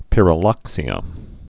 (pĭrə-lŏksē-ə, pĭryə-)